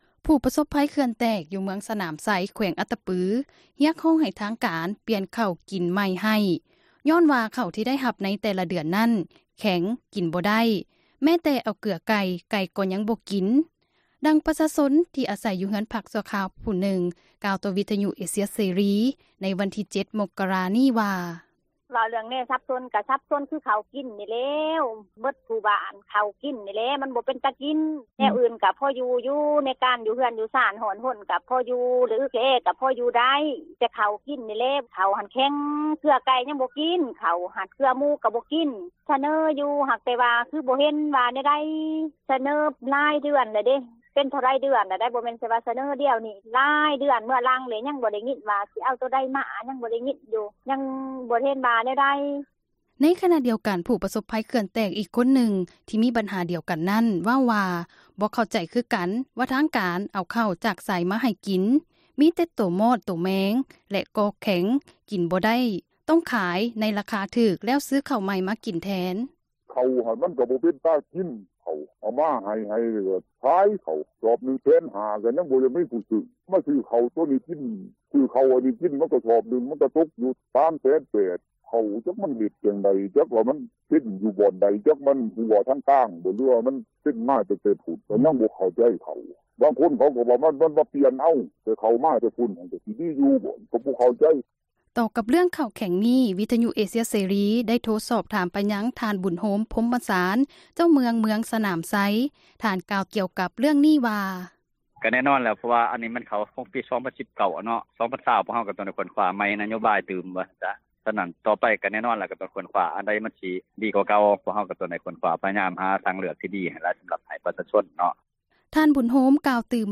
ກ່ຽວກັບເຣື້ອງເຂົ້າກິນແຂງນີ້ ວິທຍຸ ເອເຊັຽເສຣີ ໄດ້ໂທຣະສັບ ສອບຖາມ ໄປຍັງ ທ່ານ ບຸນໂຮມ ພົມມະສານ ເຈົ້າເມືອງ ເມືອງສນາມໄຊ ທ່ານກ່າວ ກ່ຽວກັບເຣື້ອງນີ້ວ່າ: